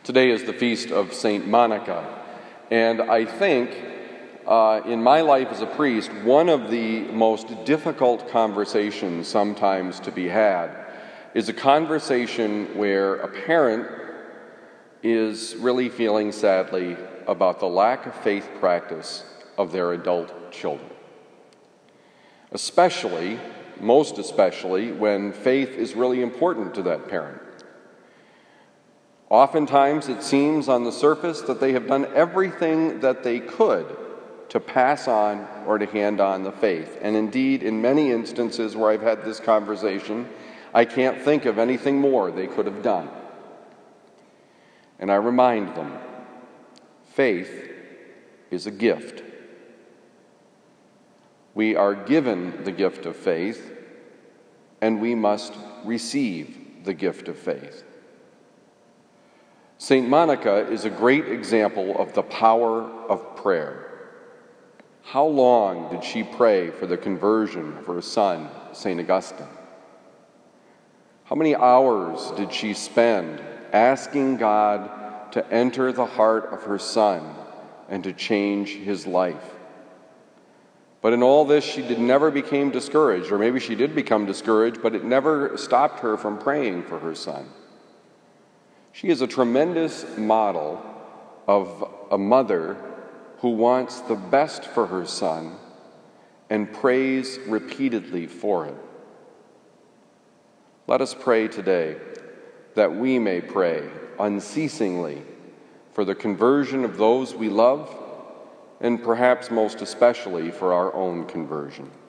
Homily given at Christian Brothers College High School, Town and Country, Missouri